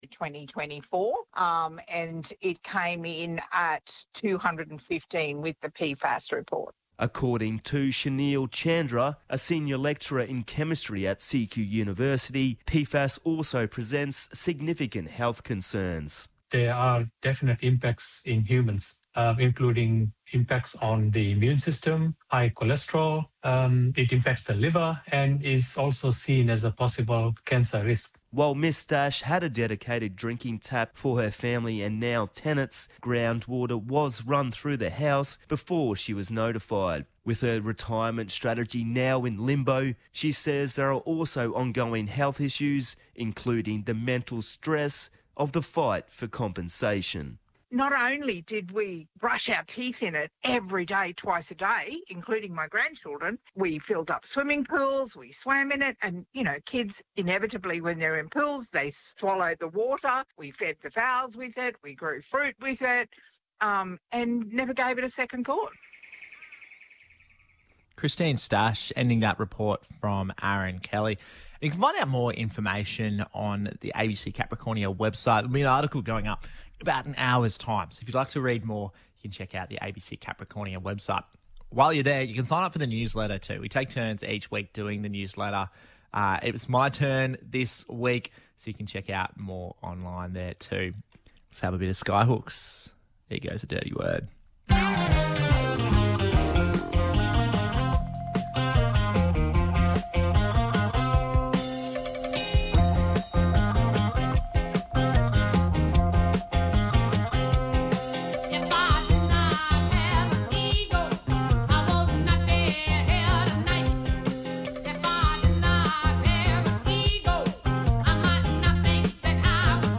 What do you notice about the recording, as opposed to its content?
Here is a media interview (below) with ABC Capricornia Radio on PFAS findings in Rockhampton and its wider impacts in the community.